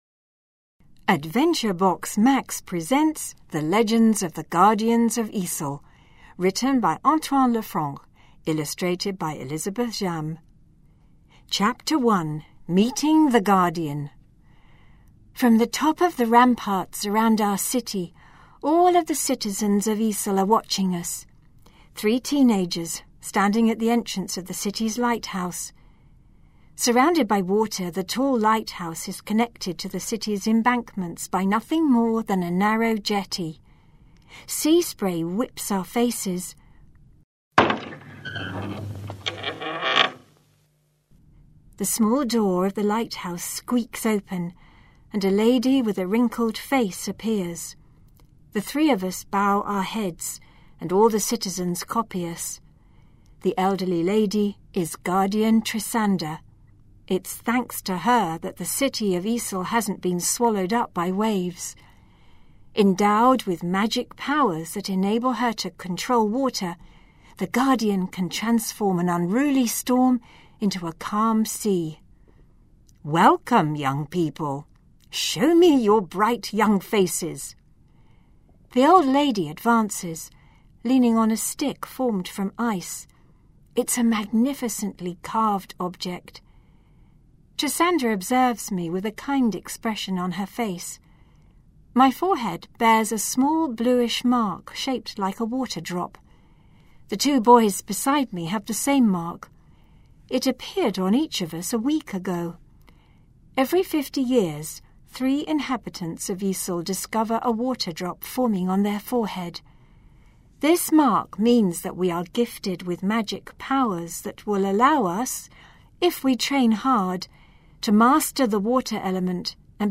The recording by professional actors brings the stories to life while helping with the comprehension and the pronunciation.